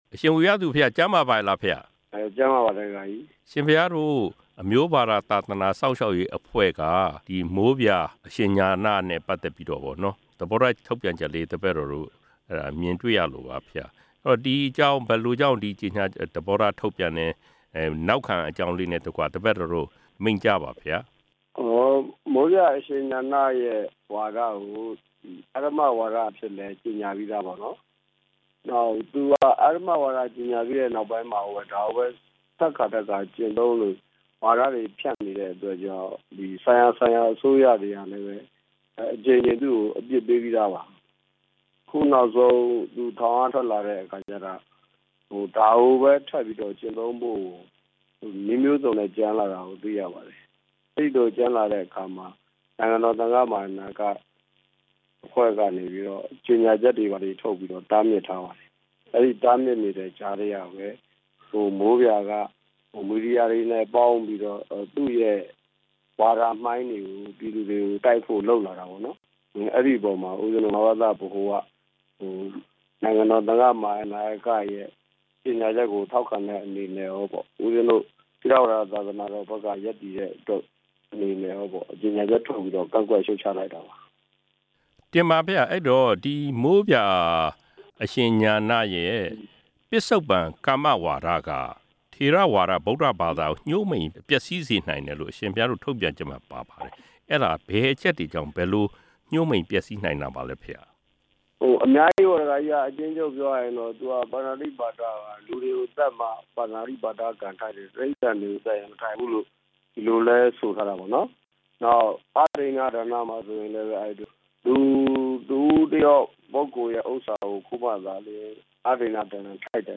နိုင်ငံတော် သံဃမဟာနာယကအဖွဲ့ရဲ့ ဝိနည်းဓိုရ်အဖွဲ့က မိုးပြာအရှင် ဉာဏ ကို အဓမ္မဝါဒီဟုလည်းကောင်း မိုးပြာအရှင် ဉာဏရဲ့ ပစ္စပ္ပန်ကမ္မဝါဒကို အဓမ္မဝါဒဟုလည်းကောင်း ဆုံးဖြတ်ခဲ့တာကို လိုက်နာခြင်းမရှိဘဲ ဆက်လက်ဆောင်ရွက်နေတဲ့အတွက် အခုလို ထုတ်ပြန်ရတာဖြစ်တယ်လို့ မဘသအဖွဲ့ ဦးဆောင်ဆရာတော် တပါးဖြစ်တဲ့ မန္တလေး မစိုးရိမ်တိုက်ဆရာတော် အရှင် ဝီရသူ က RFA ကို မိန့်ကြားပါတယ်။